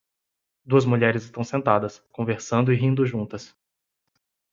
Pronounced as (IPA) /ˈʒũ.tɐs/